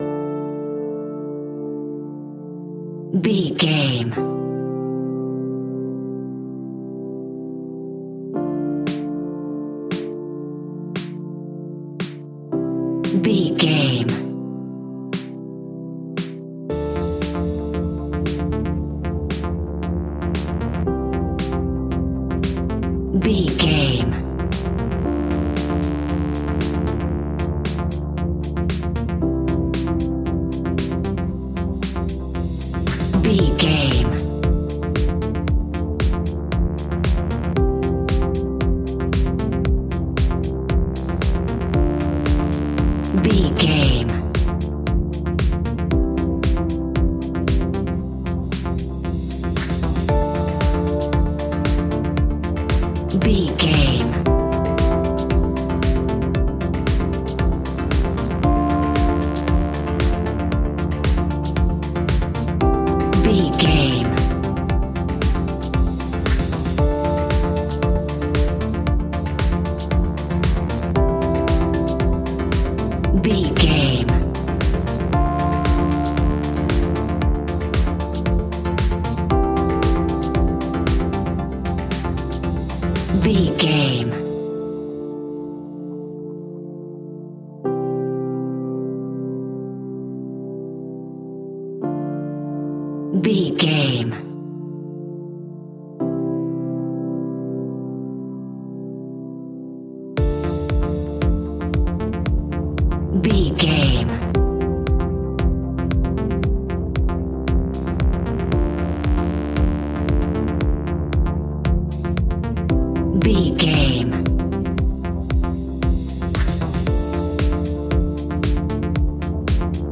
Aeolian/Minor
funky
groovy
uplifting
driving
energetic
drum machine
electric piano
synthesiser
electro house
funky house
instrumentals
synth leads
synth bass